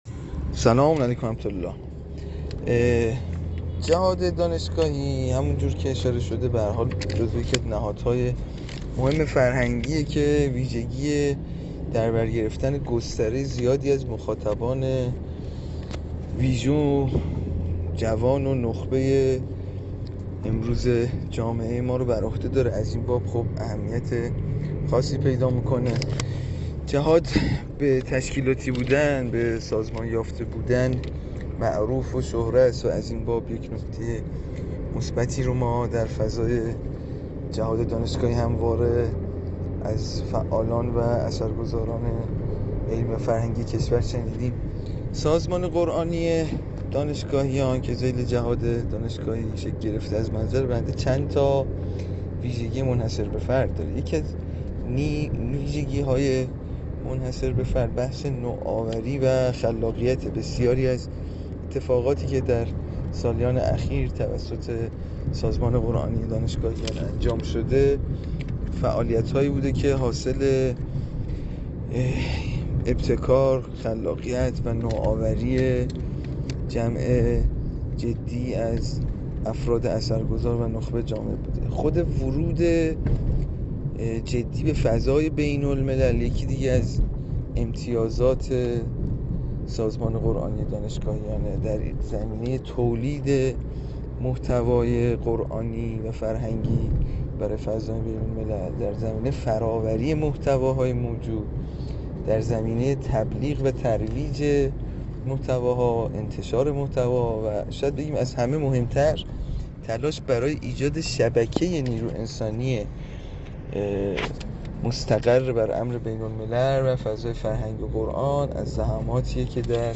همزمان با چهل‌وسومین سالروز تأسیس جهاددانشگاهی، طی گفت‌وگویی با حجت‌الاسلام والمسلمین علی تقی‌زاده، رئیس سازمان دارالقرآن الکریم نظر وی را درباره فعالیت‌های فرهنگی جهاددانشگاهی به ویژه امور قرآن و عترت آن جویا شدیم.